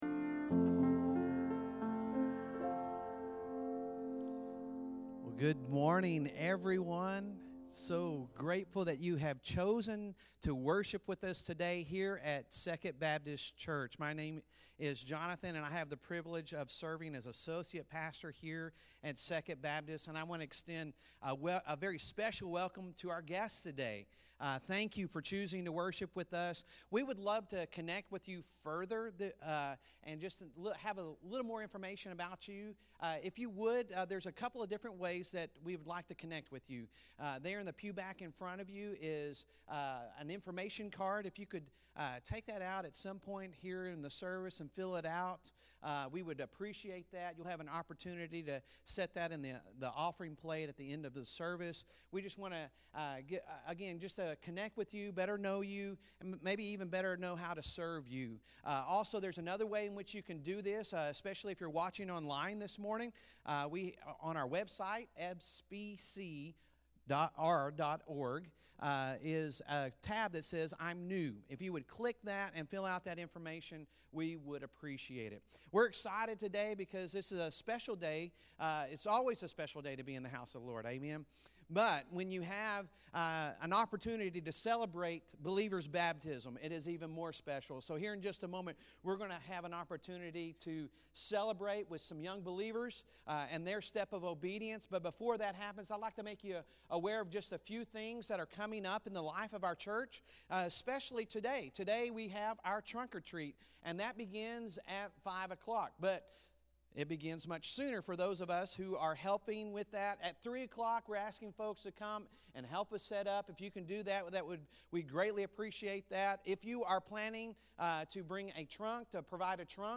Sunday Sermon October 30, 2022